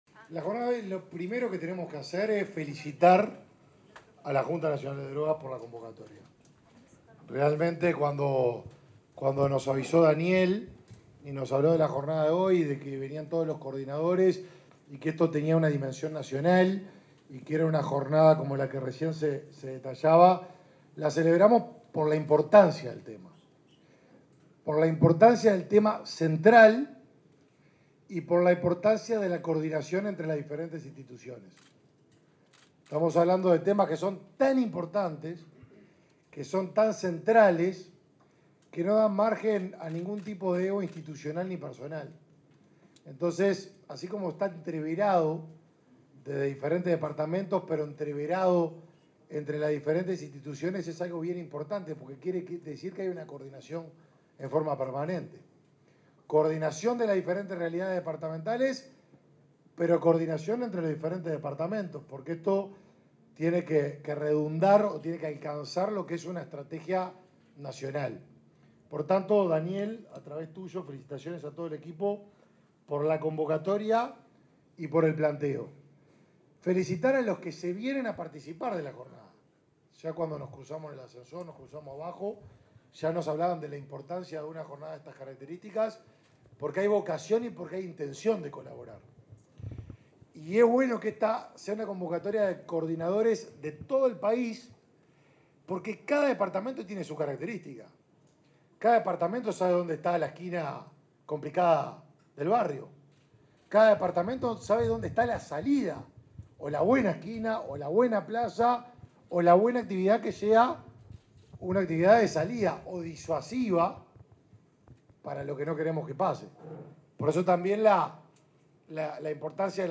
Palabras de autoridades en Encuentro de Juntas Departamentales y Locales de Drogas
Palabras de autoridades en Encuentro de Juntas Departamentales y Locales de Drogas 06/07/2023 Compartir Facebook X Copiar enlace WhatsApp LinkedIn El ministro de Desarrollo Social, Martín Lema; el subsecretario de Salud Pública; José Luis Satjian; el presidente del INAU, Pablo Abdala, y el titular de la Secretaría Nacional de Drogas, Daniel Radío, participaron, en Montevideo, en el Encuentro Nacional de Juntas Departamentales y Locales de Drogas.